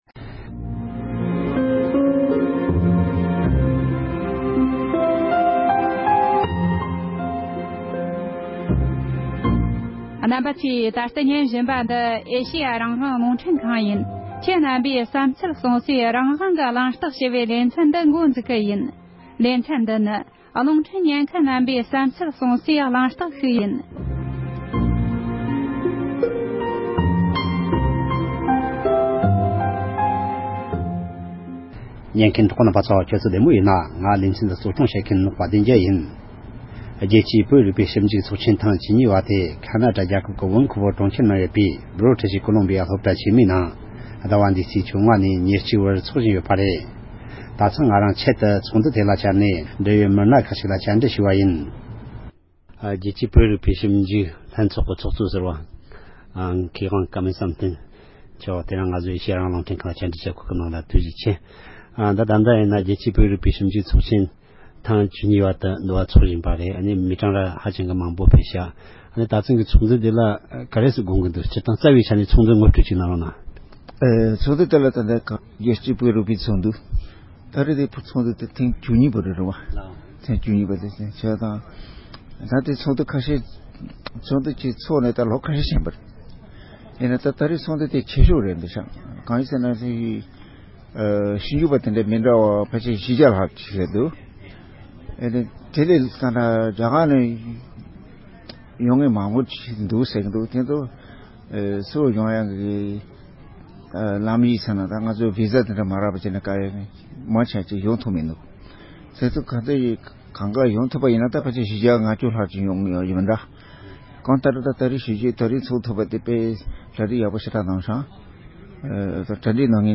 ༄༅༎དེ་རིང་གི་རང་དབང་གི་གླེང་སྟེགས་ཞེས་པའི་ལེ་ཚན་ནང་དུ། རྒྱལ་སྤྱིའི་བོད་རིག་པའི་ཞིབ་འཇུག་ཚོགས་ཆེན་ཐེངས་བཅུ་གཉིས་པ་དེ། ཁེ་ན་ཌའི་གྲོང་ཁྱེར་ལྦེན་ཁུ་བར་ནང་ཡོད་པའི་ལྦིར་ཊིཤ་ཀོ་ལོམ་རྦི་ཡ་སློབ་གྲྭ་ཆེན་མོའི་ནང་ཚོགས་བཞིན་ཡོད་པ་དེར། དཔལ་ལྡན་རྒྱལ་ས་གནས་སུ་ཆེད་བཅར་གྱིས་ཚོགས་ཞུགས་བ་ཁག་ཅིག་ལ་སོ་སོའི་ཞིབ་འཇུག་གི་རྩོམ་ཡིག་དང་ཚོགས་འདུའི་སྐོར་བཀའ་འདྲི་ཞུས་པ་ཞིག་ལ་གསན་རོགས་གནོངས༎